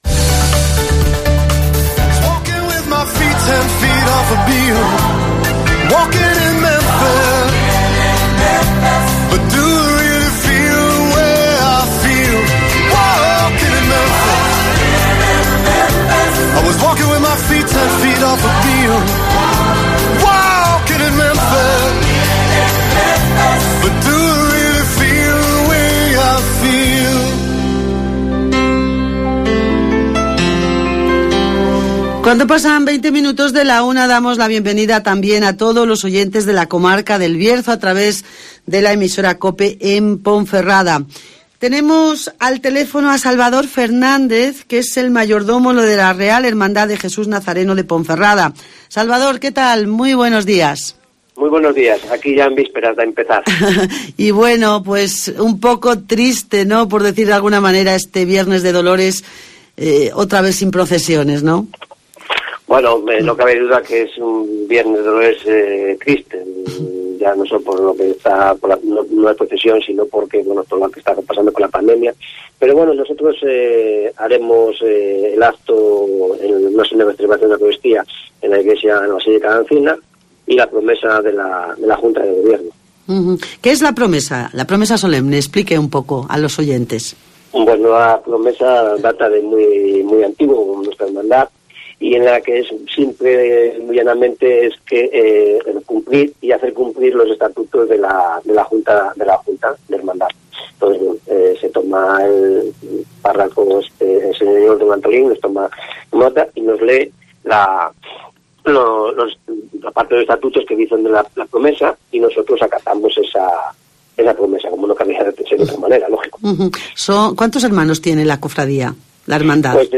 Semana Santa de Ponferrada 2021 (Entrevista